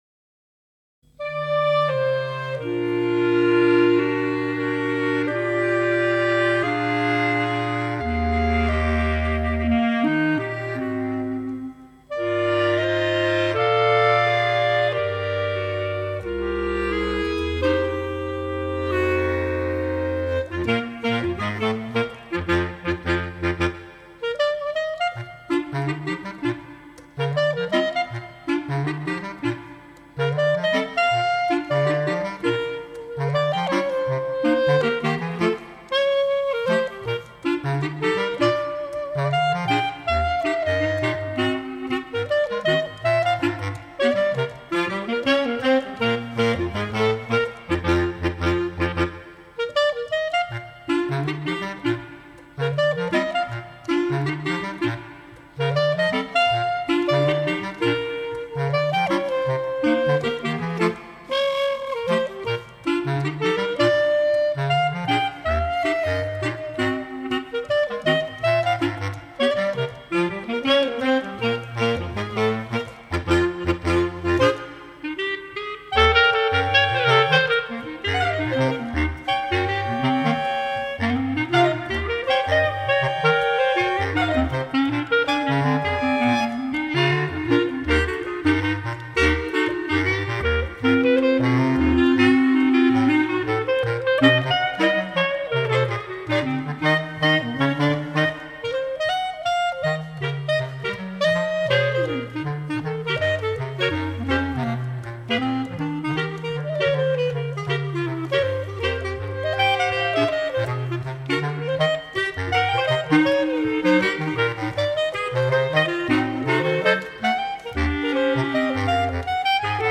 Bb Clarinet Range: E1 to C#3. Bass Clarinet Lowest Note: E1
Dixieland Jazz